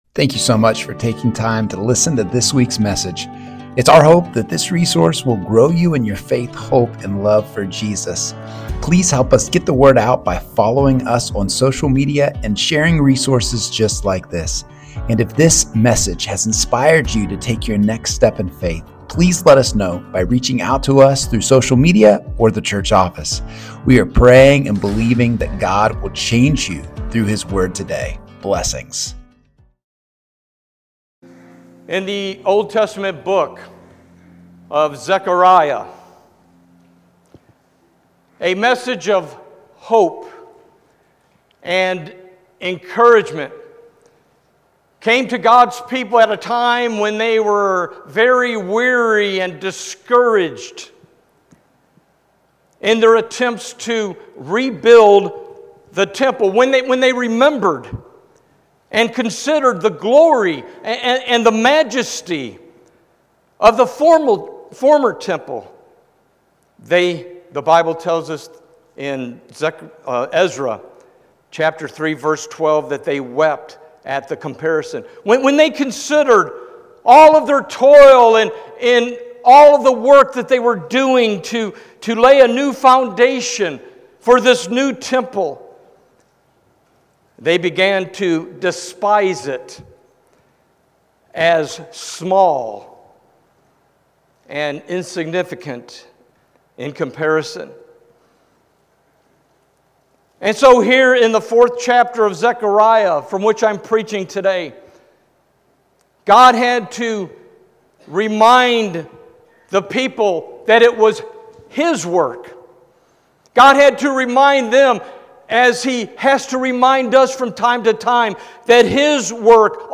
Sermons | First Baptist Church of St Marys